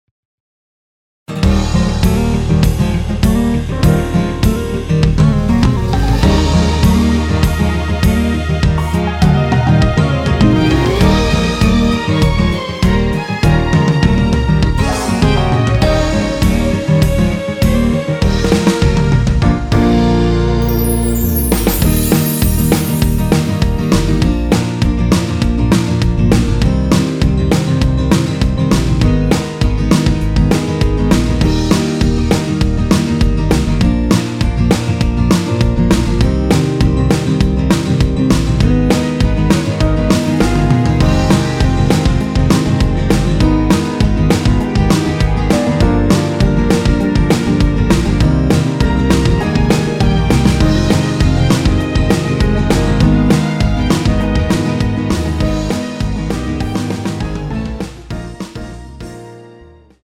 원키에서(-3)내린 MR입니다.
Eb
앞부분30초, 뒷부분30초씩 편집해서 올려 드리고 있습니다.
중간에 음이 끈어지고 다시 나오는 이유는